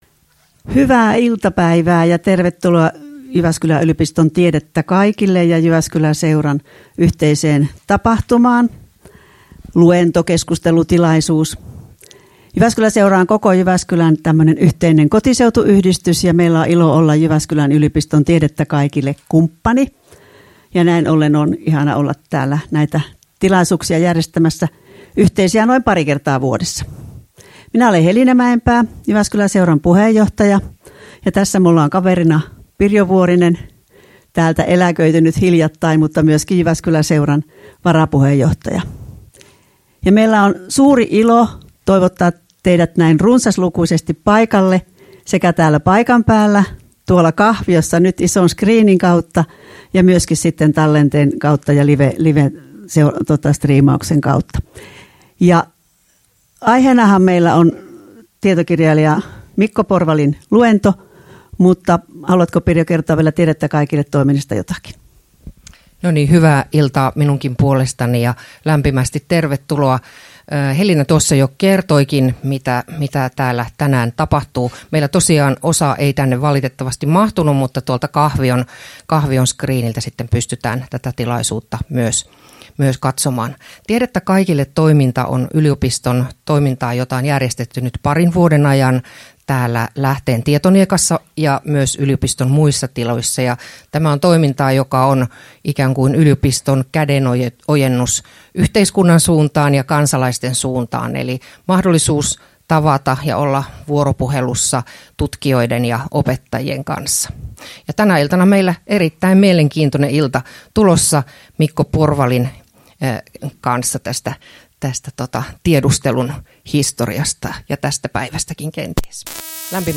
Luento 25.3.2025